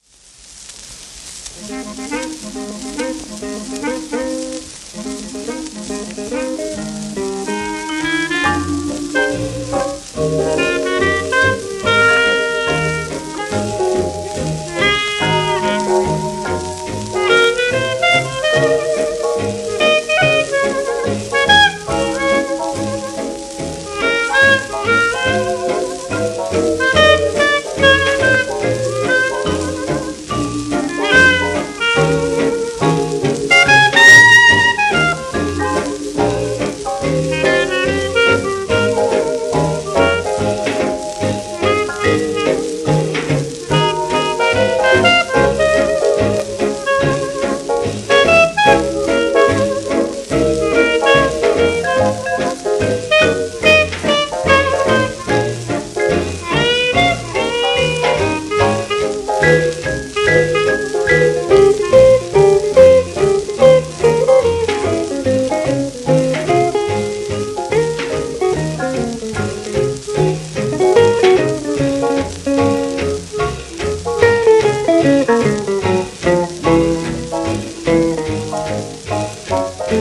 盤質:A-~B+ *一部ややノイズあり